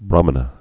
(brämə-nə)